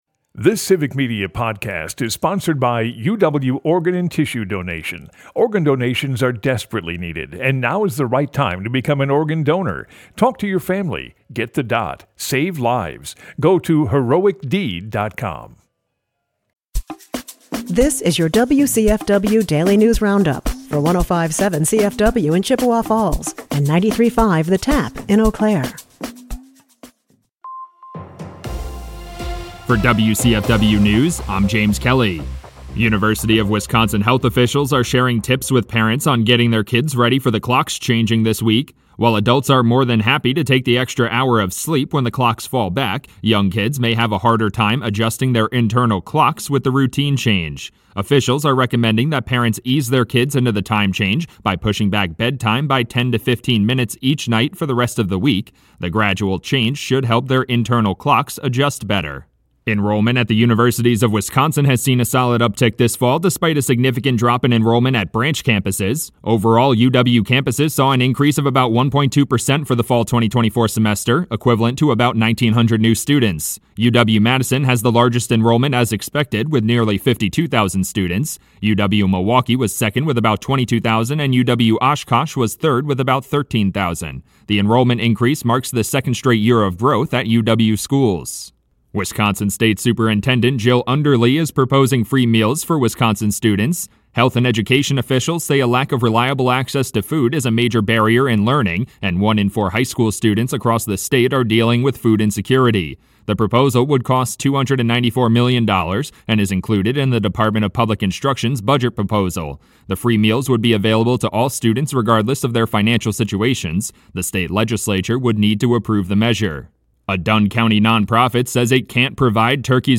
The WCFW Daily News Roundup has your state and local news, weather, and sports for Chippewa Falls, delivered as a podcast every weekday at 9 a.m. Stay on top of your local news and tune in to your community!